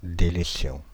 Ääntäminen
France (Île-de-France): IPA: /de.le.sjɔ̃/